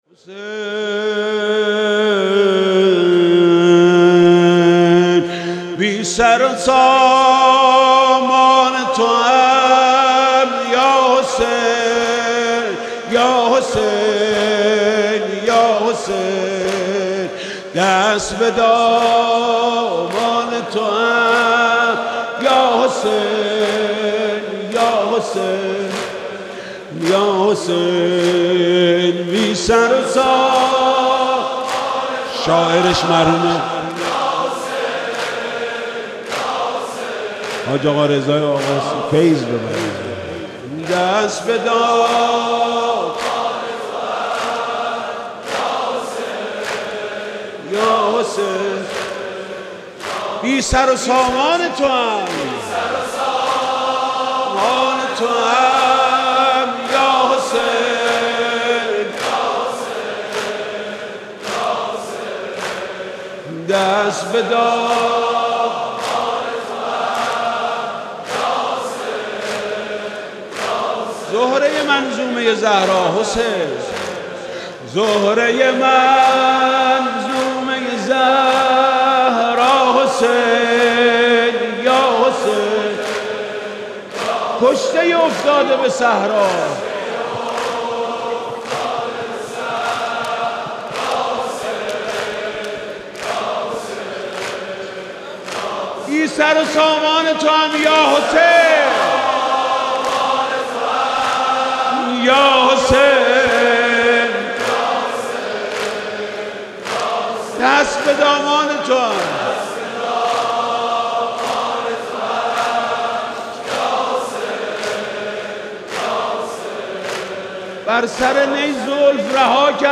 حاج میثم مطیعی/گلچین مداحی های شب هفتم تا دوازدهم ماه مبارک رمضان97
عقیق:حاج میثم مطیعی/گلچین مداحی های شب هفتم تا دوازدهم ماه مبارک رمضان97/هئیت میثاق با شهدا